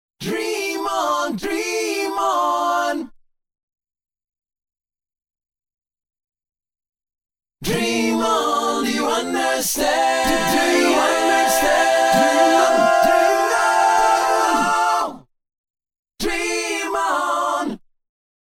Samples of early demos, unused takes and such.